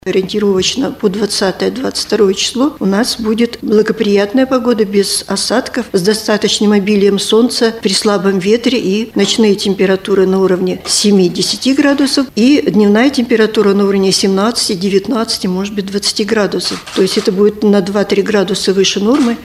в центре «ТАСС-Урал»